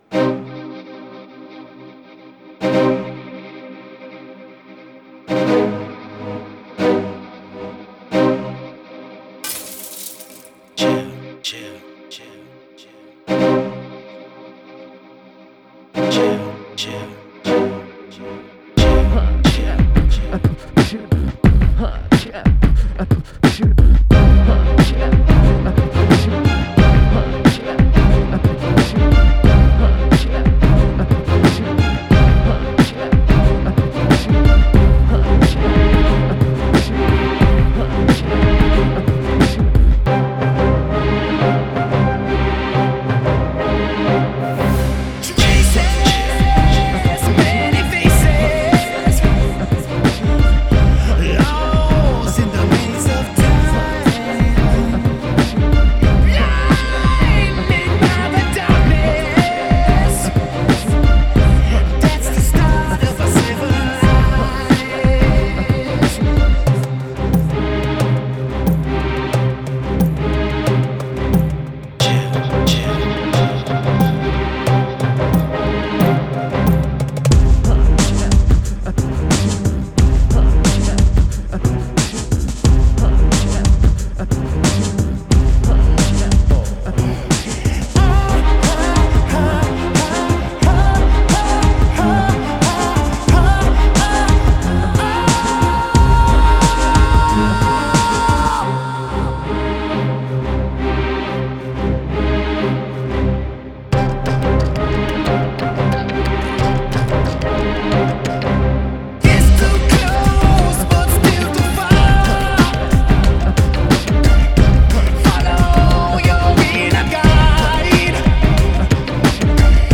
Жанр: New Age; Битрэйт